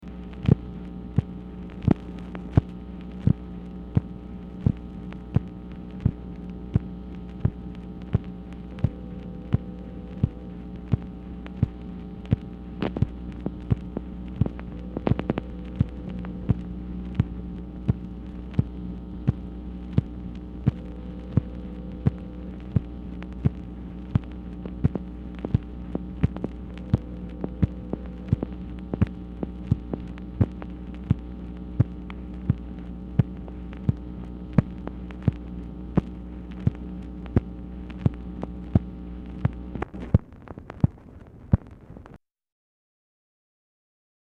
Telephone conversation # 10966, sound recording, MACHINE NOISE, 10/16/1966, time unknown | Discover LBJ
Telephone conversation
Format Dictation belt